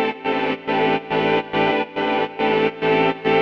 Index of /musicradar/sidechained-samples/140bpm